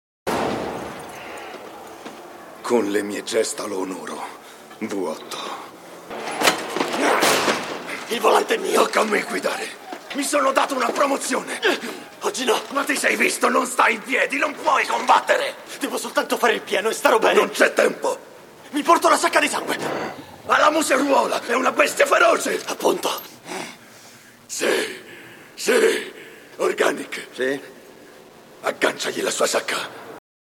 nel film "Mad Max: Fury Road", in cui doppia Josh Helman.